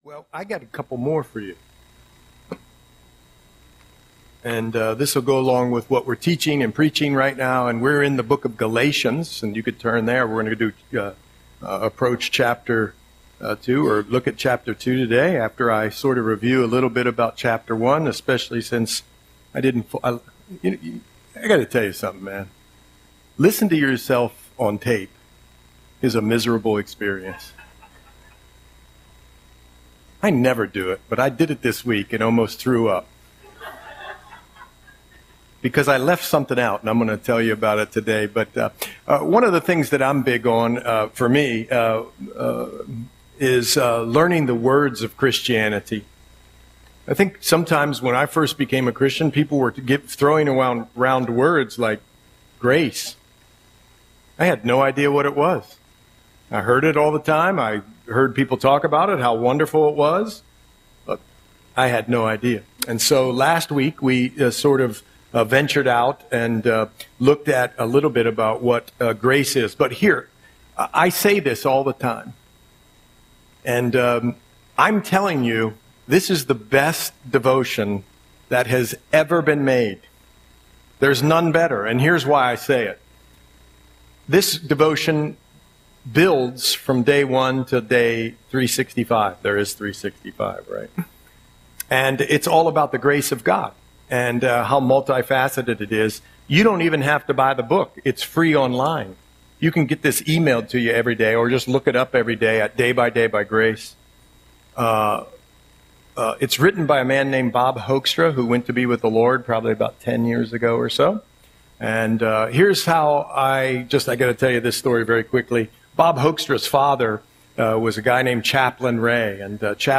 Audio Sermon - May 25, 2025